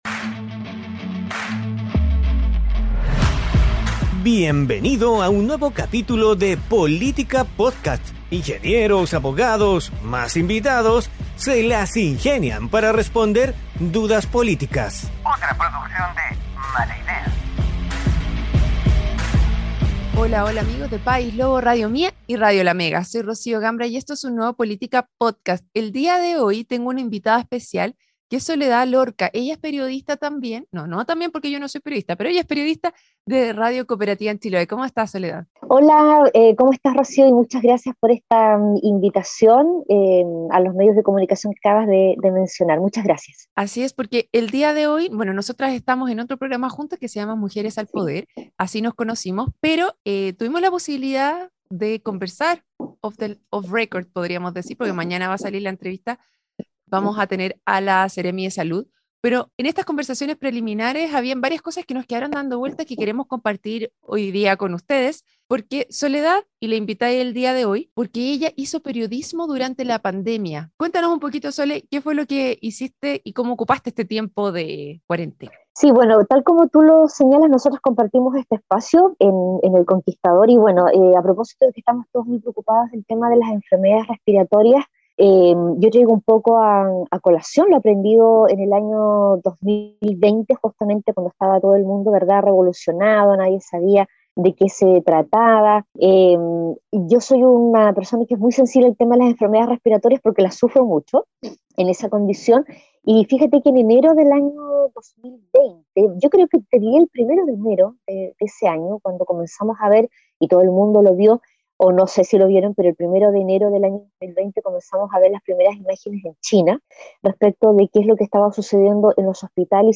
programa donde junto a panelistas estables e invitados tratan de responder dudas políticas.